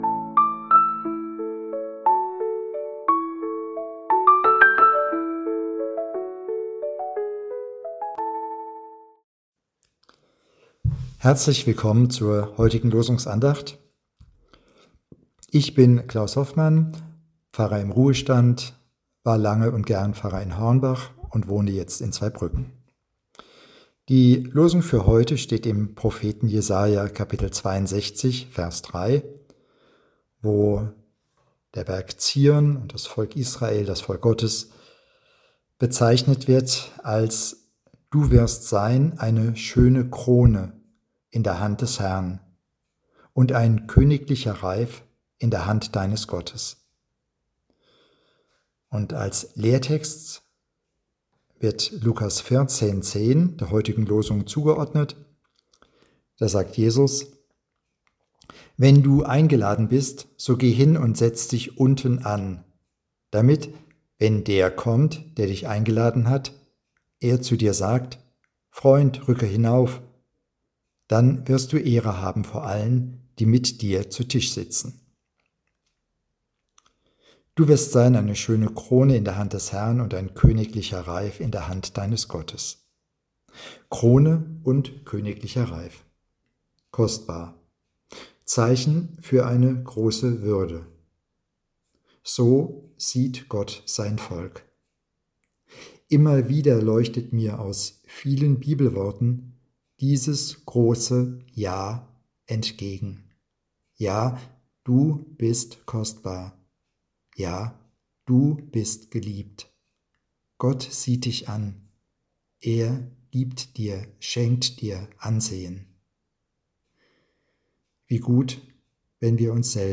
Losungsandacht für Donnerstag, 28.08.2025